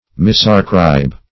Misarcribe \Mis`ar*cribe"\, v. t. To ascribe wrongly.